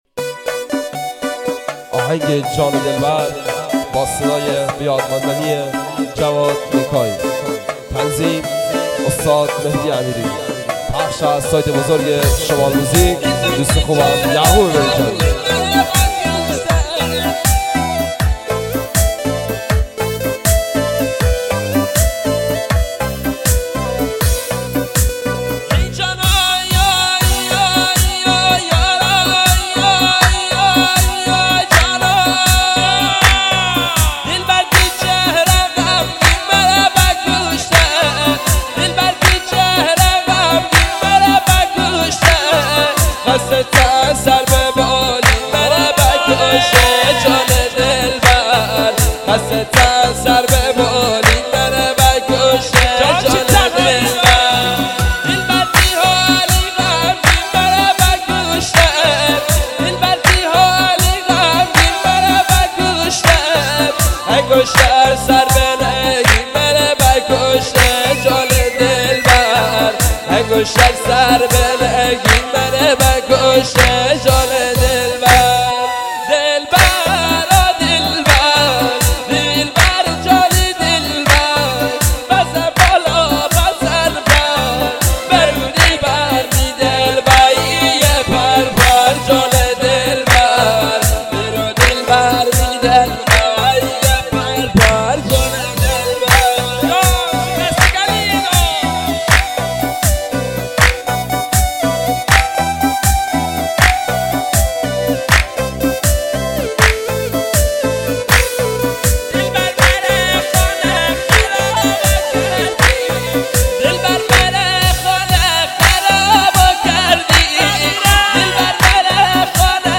شمالی مازندرانی غمگین